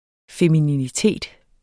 Udtale [ femininiˈteˀd ]